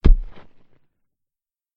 Глухой звук удара кулаком о землю